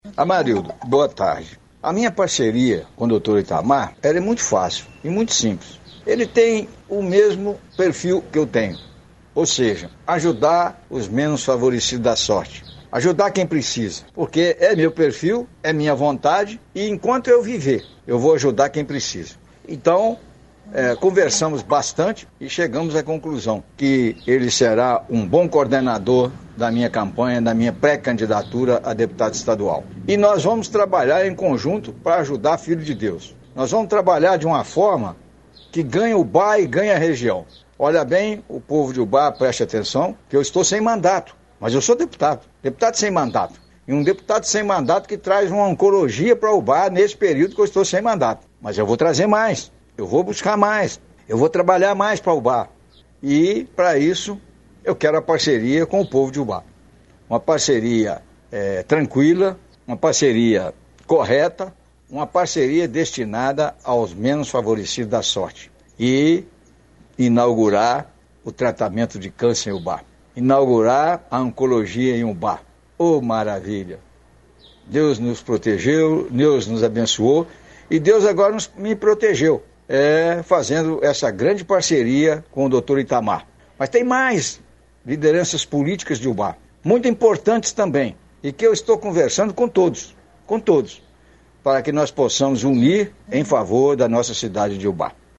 Por mensagem de voz
Ex-deputado Dirceu Ribeiro fala da parceria